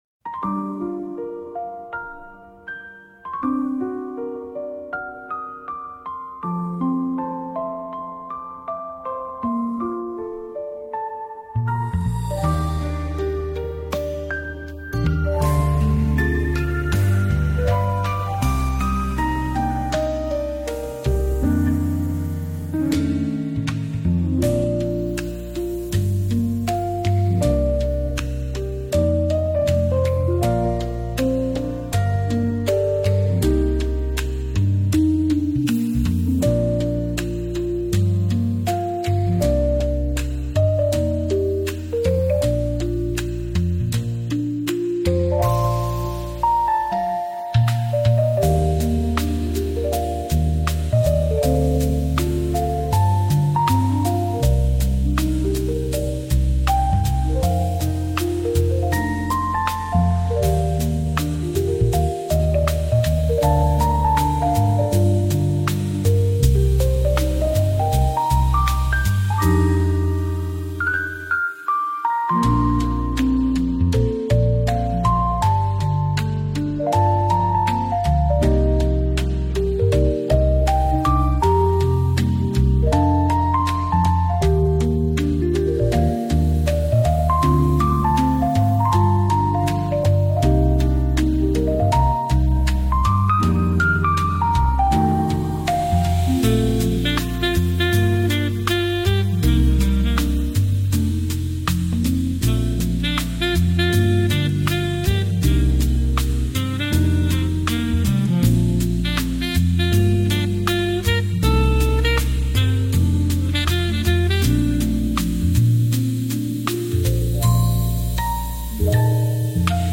最初暗い感じになったのですがそこから何故かカフェ風になりました…